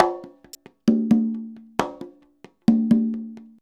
133CONGA03-R.wav